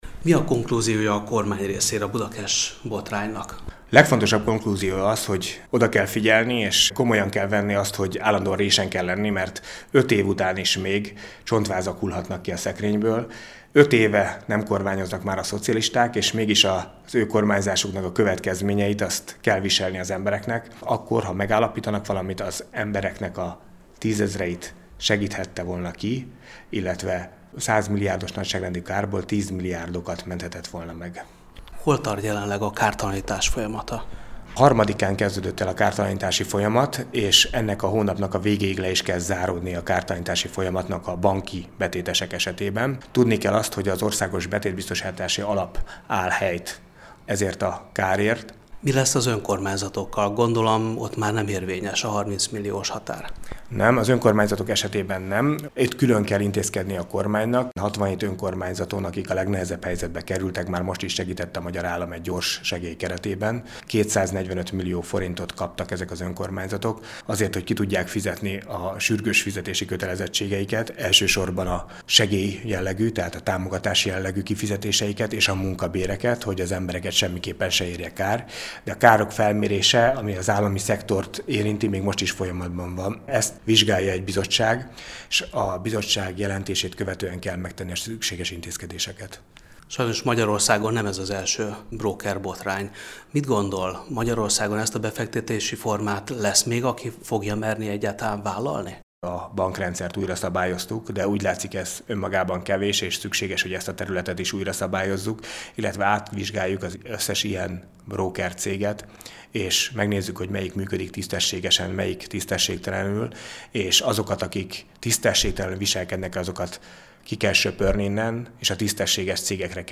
A Tuzson Bencével készült interjút